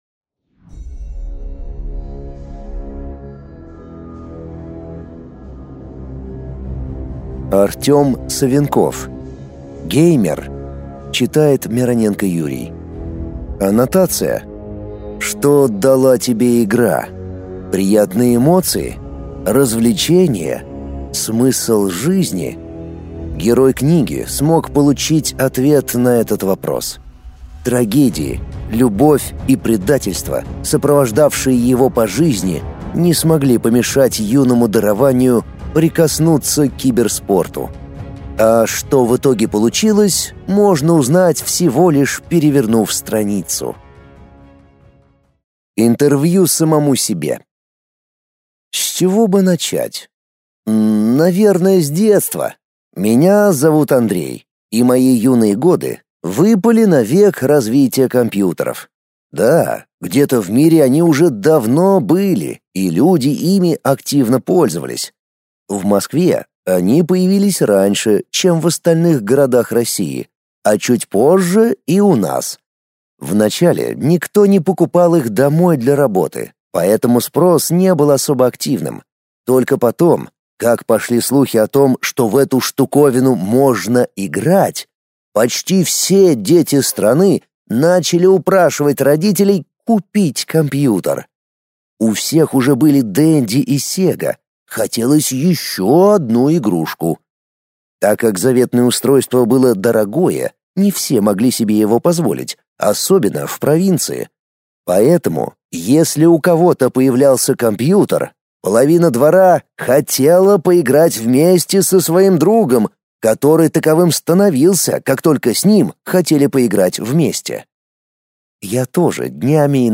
Аудиокнига Геймер | Библиотека аудиокниг